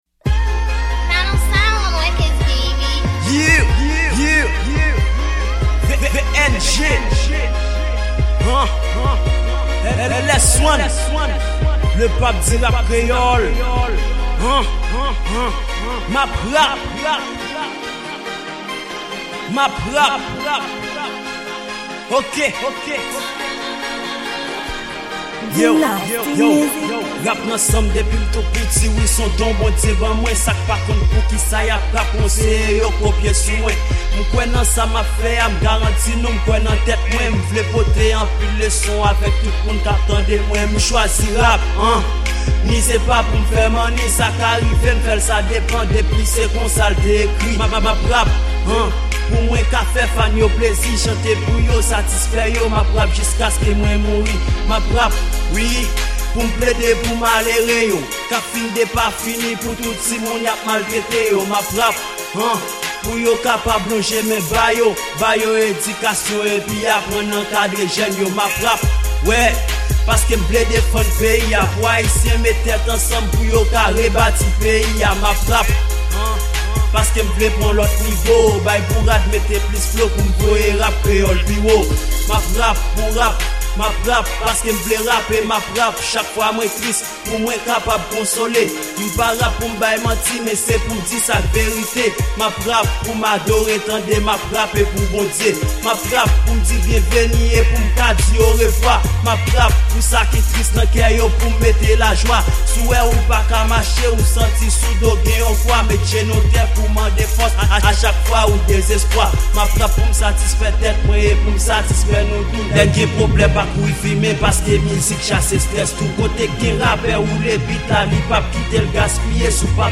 Freestyle
Genre: Rap